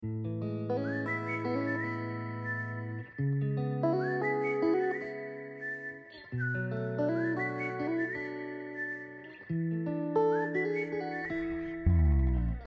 smooth vibes
i finally am letting my whistle skills be public knowledge